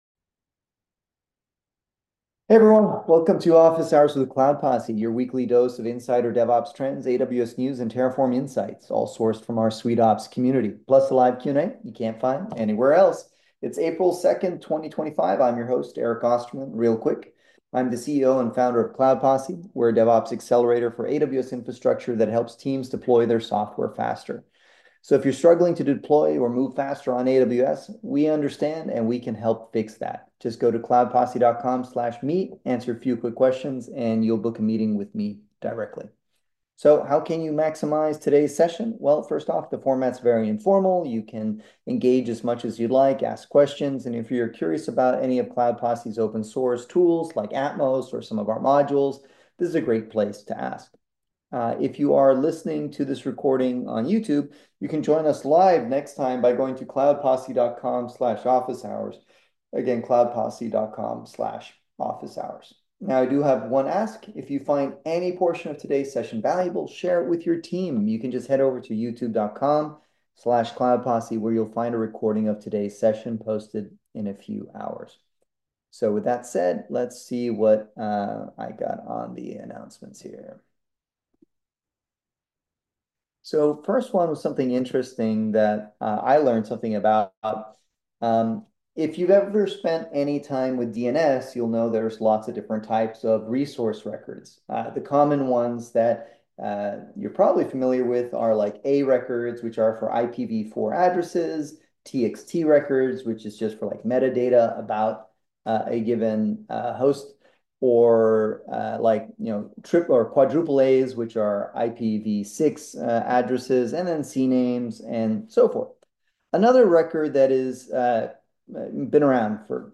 Cloud Posse holds LIVE "Office Hours" every Wednesday to answer questions on all things related to AWS, DevOps, Terraform, Kubernetes, CI/CD.